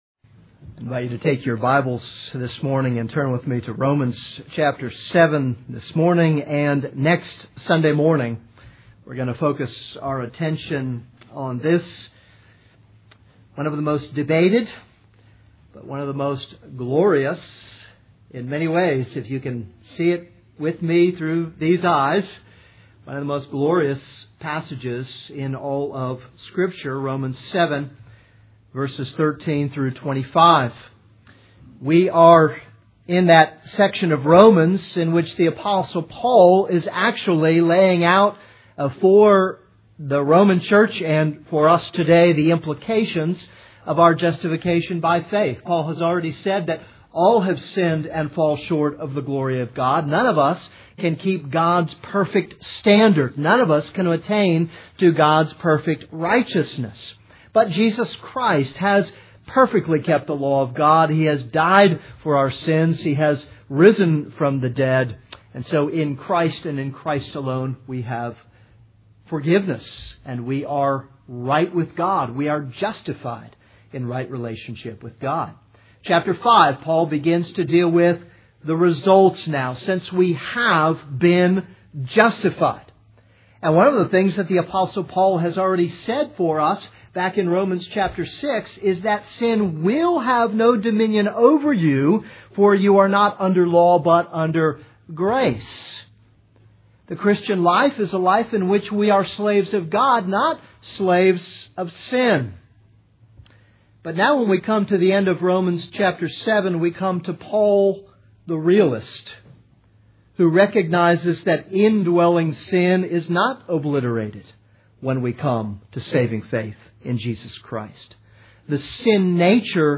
This is a sermon on Romans 7:13-25.